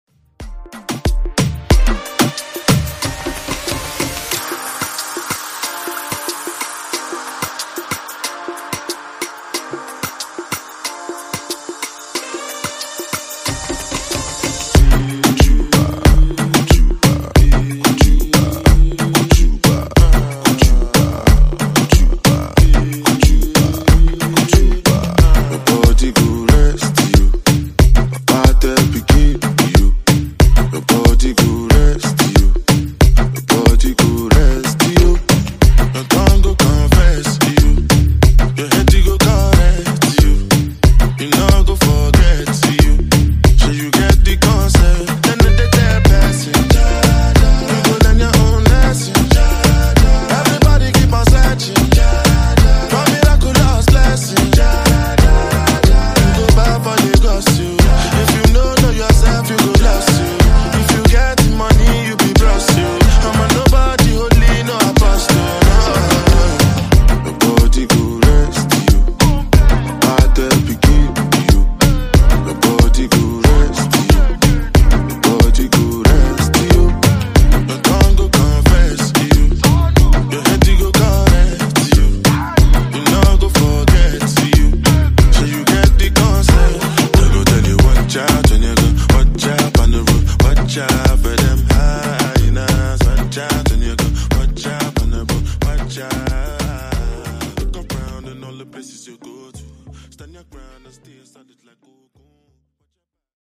Genre: RE-DRUM
Dirty BPM: 94 Time